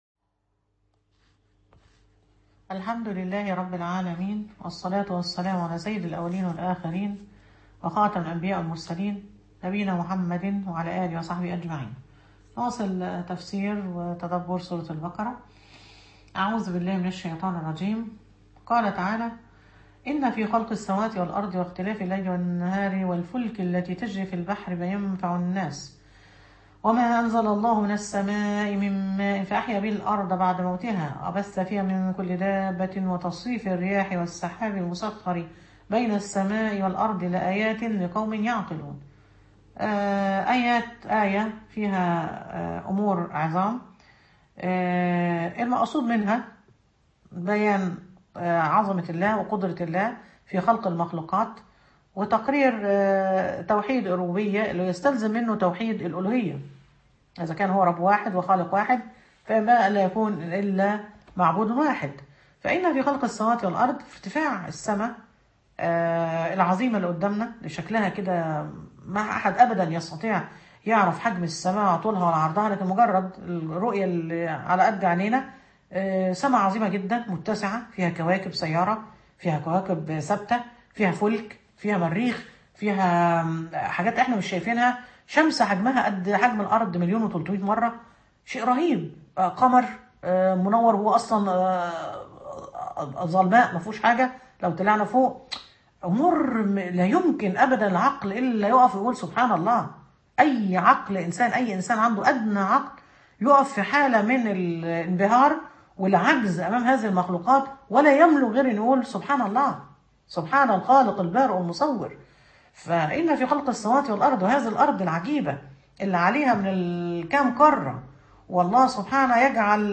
تدبر سورة البقرة المحاضرة الثامنة و العشرون من آية “164: 169”